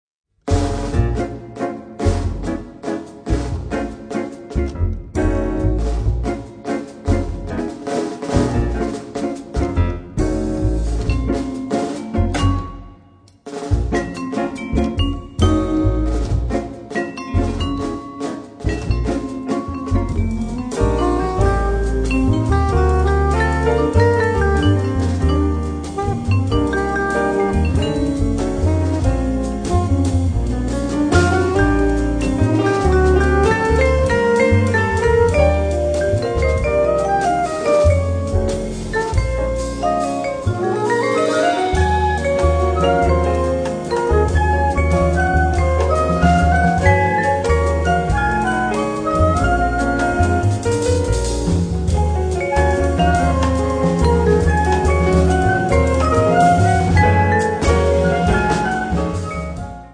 batteria
pianoforte
sax soprano